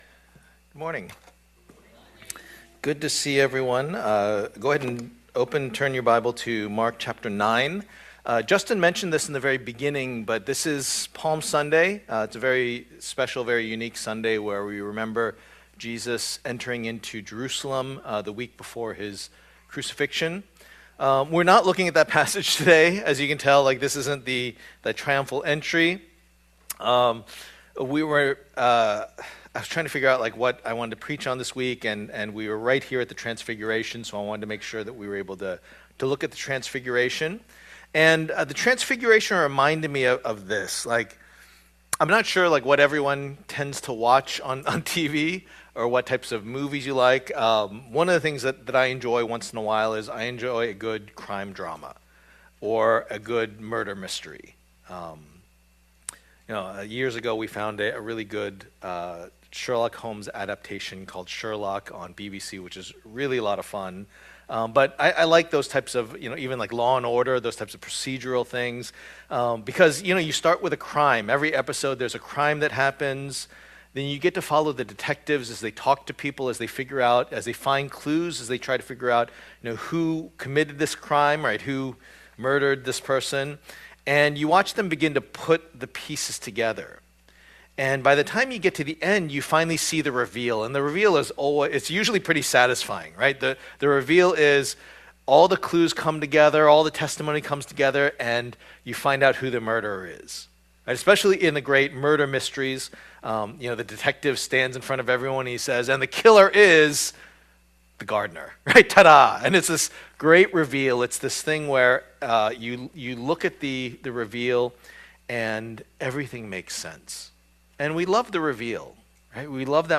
The Gospel of Mark Passage: Mark 9:2-13 Service Type: Lord's Day « The Messiah’s Mission